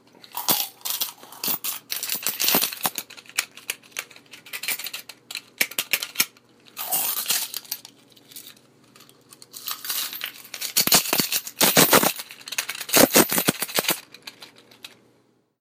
你刚刚收到钱的通知
声道立体声